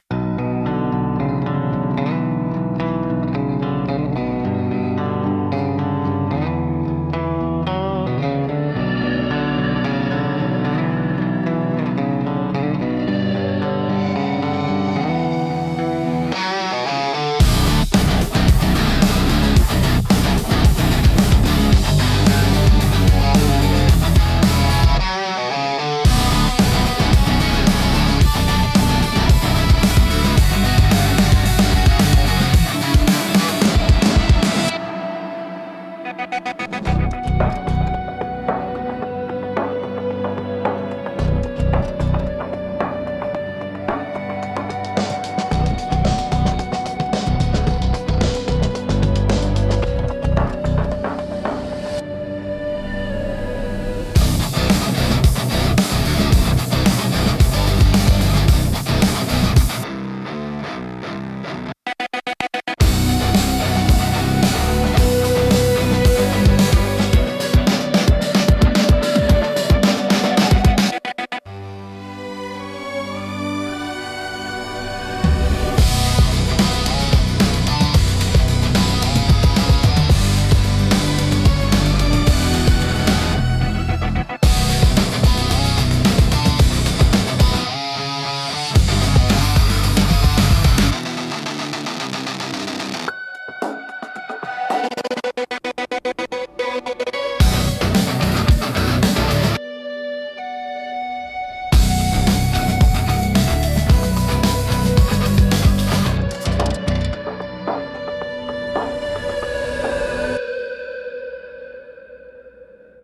Instrumental - Buried Treasure Beneath the Bridge